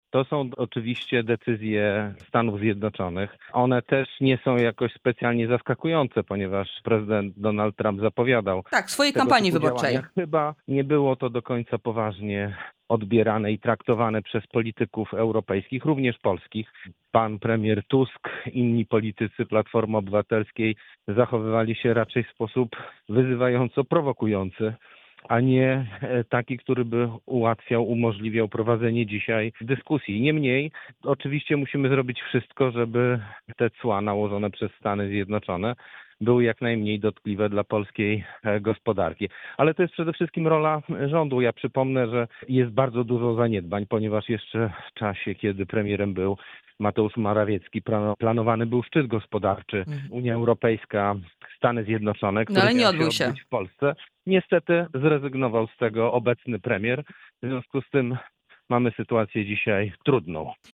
Michał Dworczyk był gościem wrocławskiego radia „Rodzina”